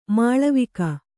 ♪ māḷavika